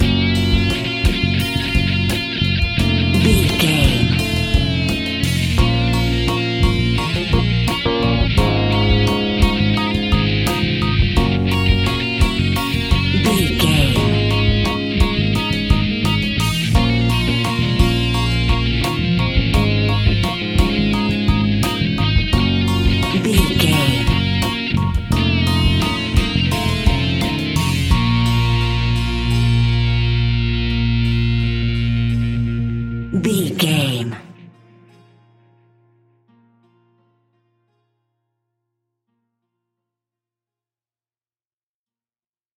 In-crescendo
Thriller
Aeolian/Minor
tension
ominous
eerie
Horror Pads
Horror Synths
Horror Ambience